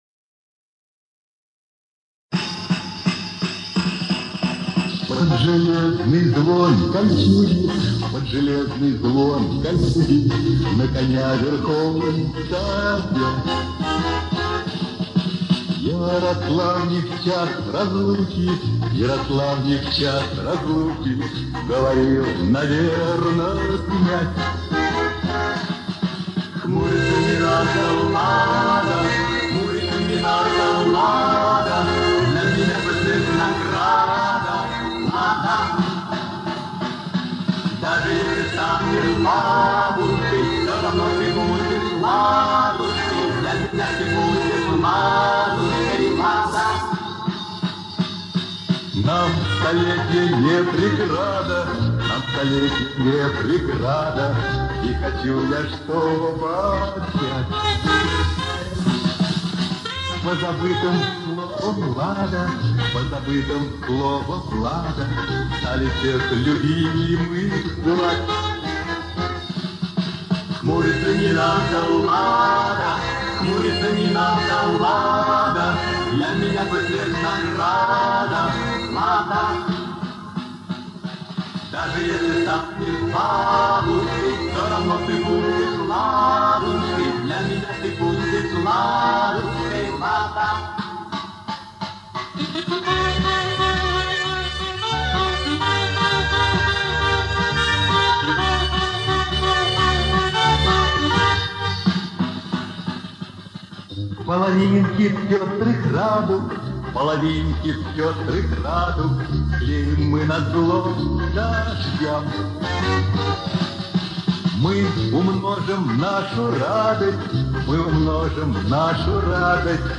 Убраны провалы, насколько можно было.